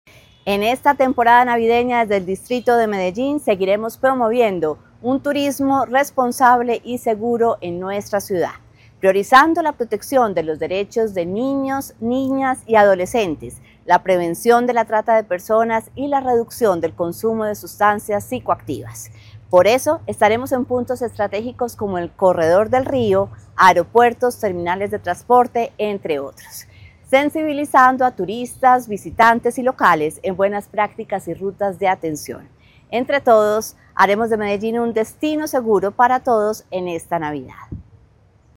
Declaraciones de la secretaria de Turismo y Entretenimiento, Ana María López Acosta
Declaraciones-de-la-secretaria-de-Turismo-y-Entretenimiento-Ana-Maria-Lopez-Acosta-1.mp3